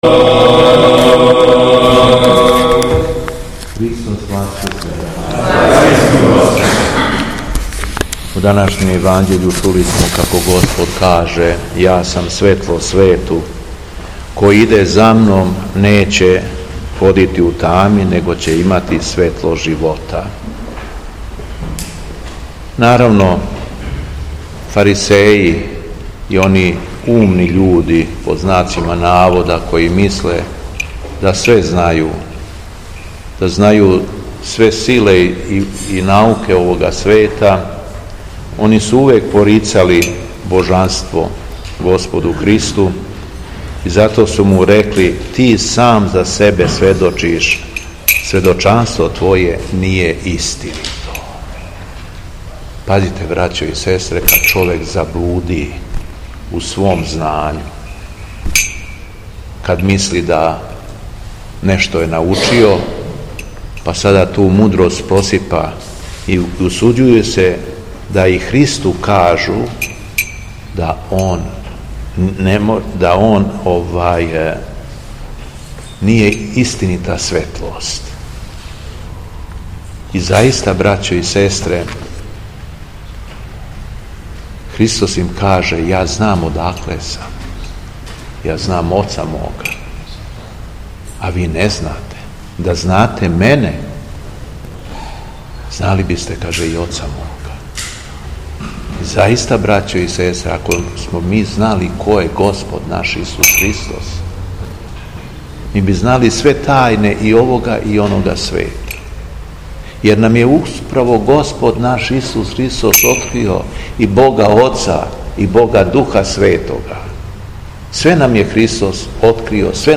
Беседа Његовог Преосвештенства Епископа шумадијског г. Јована
Беседећи верном народу, Владика Јован је рекао: